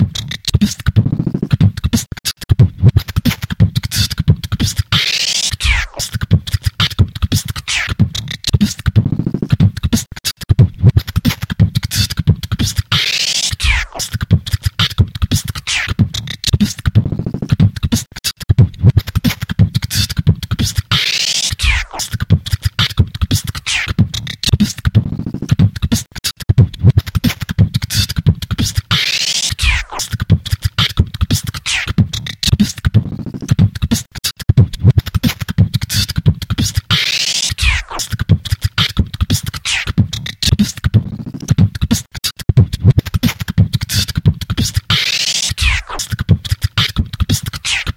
人类的节拍器" 45低矮的汤姆
描述：我说"toomquot的声音
标签： 口技
声道立体声